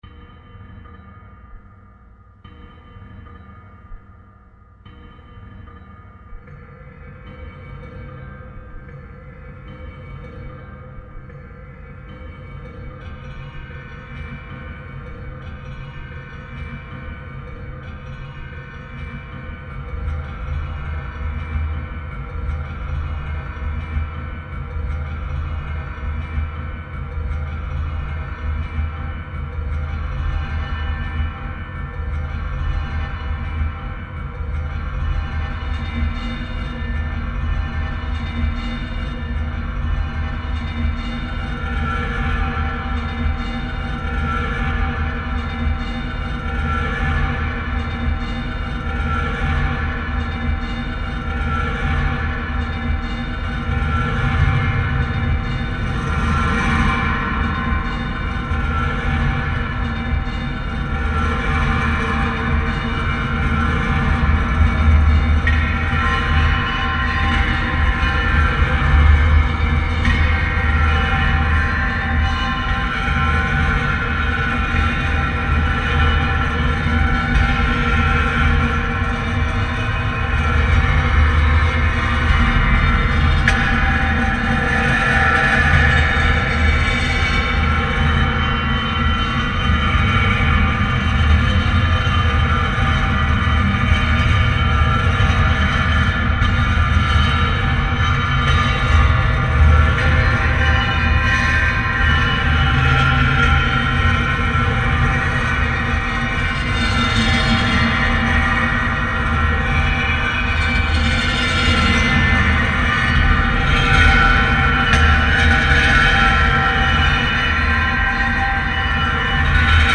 File under: Dark Ambient / Sound Art / Experimental